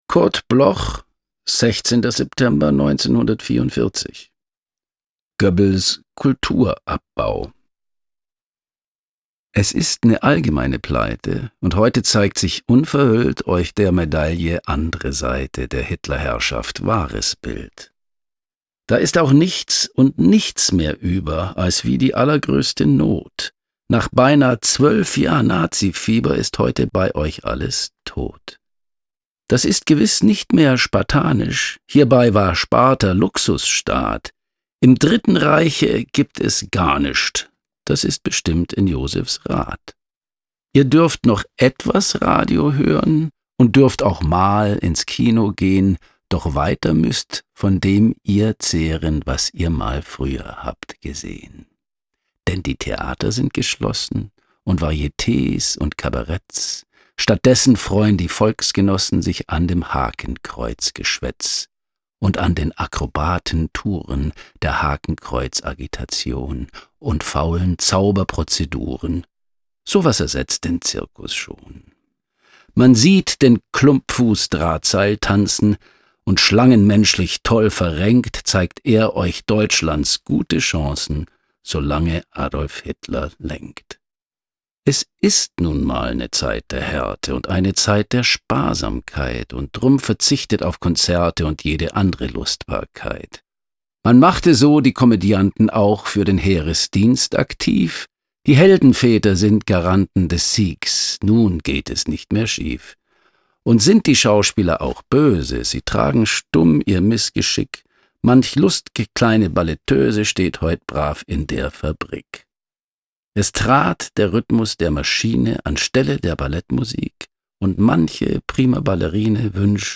Aufnahme: Gürtler Studios, Stephanskirchen · Bearbeitung: Kristen & Schmidt, Wiesbaden